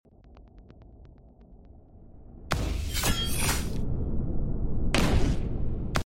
This is a part of my scifi demo reel, i accidentally posted it with music the first time💀